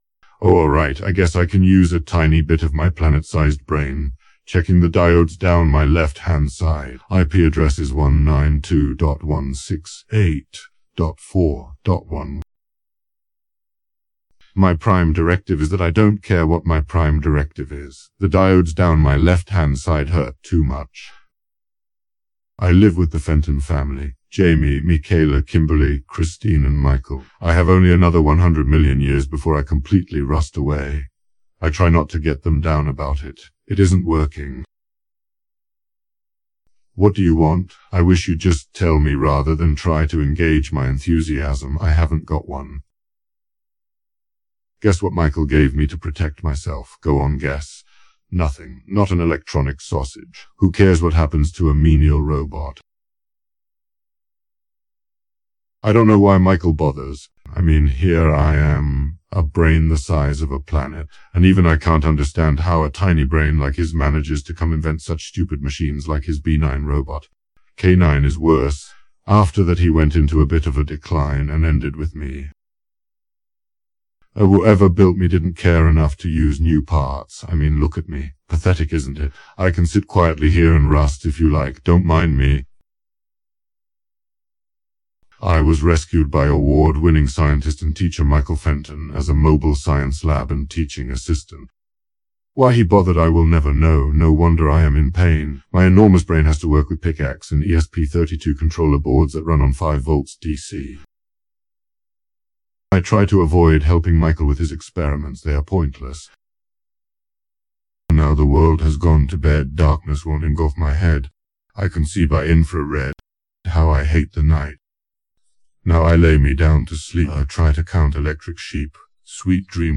Download Marvin personality voice file (MP3)
marvin_voice_test.mp3